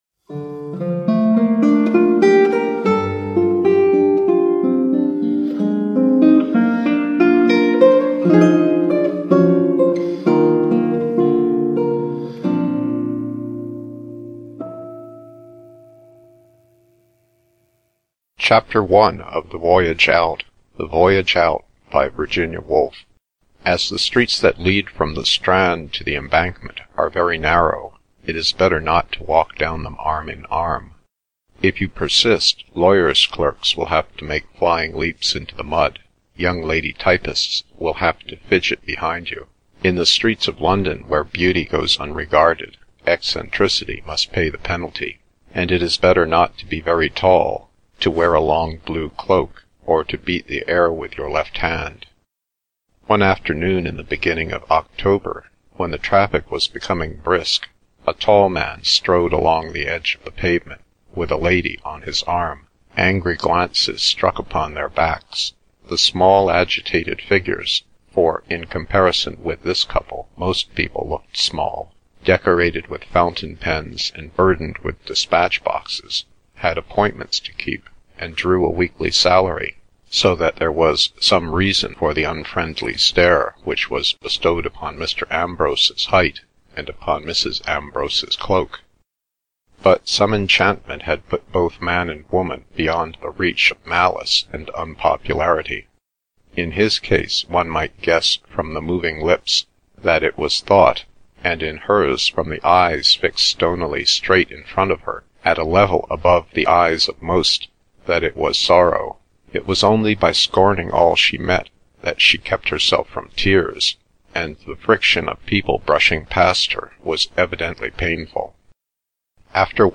The Voyage Out / Ljudbok